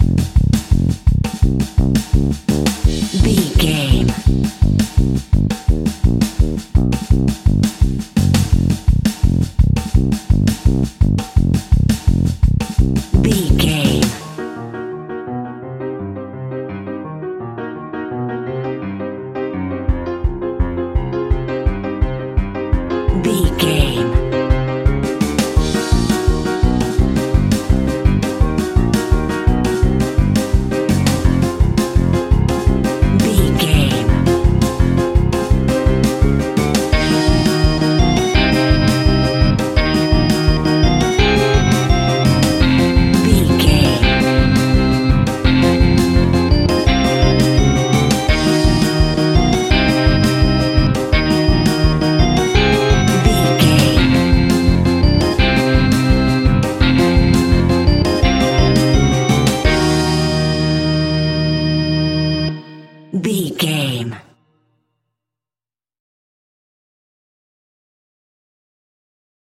Atonal
ominous
eerie
mysterious
horror music
Horror Pads
horror piano
Horror Synths